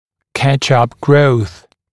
[kæʧ-ʌp grəuθ][кэч-ап гроус]«догоняющий» рост